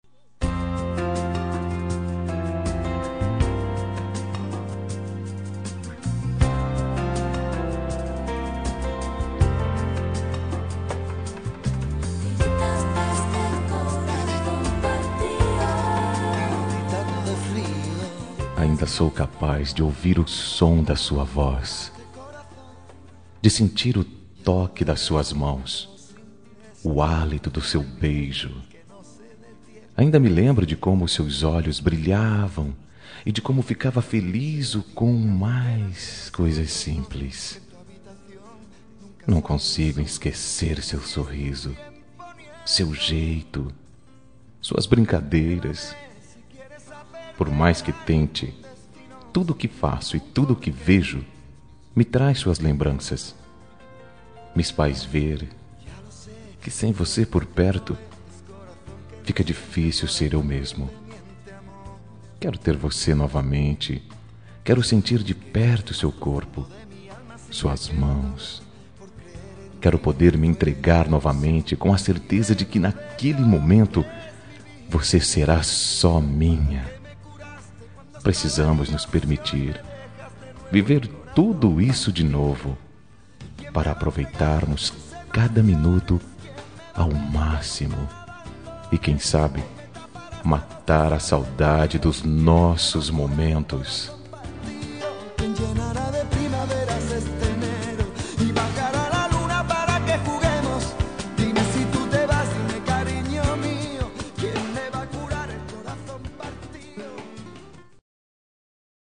Telemensagem Momentos Especiais – Voz Masculina – Cód: 201886 – Adorei a Noite